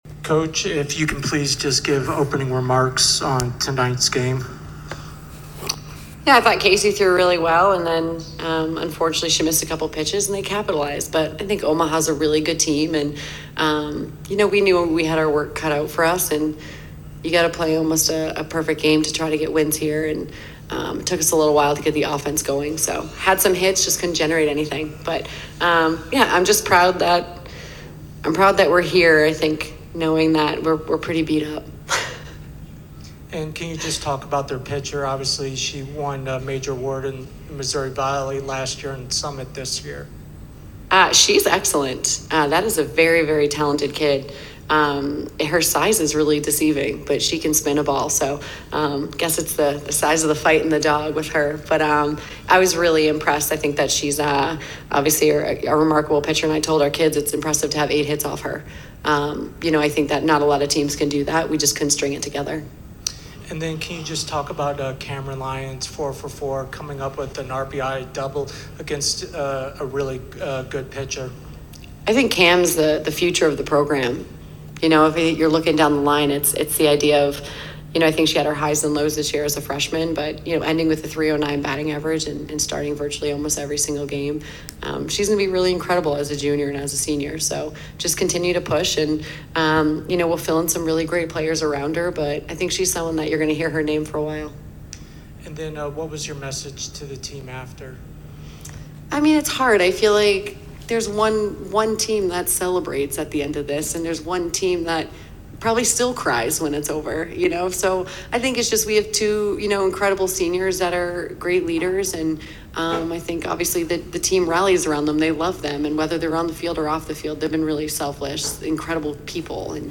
Omaha Postgame Interview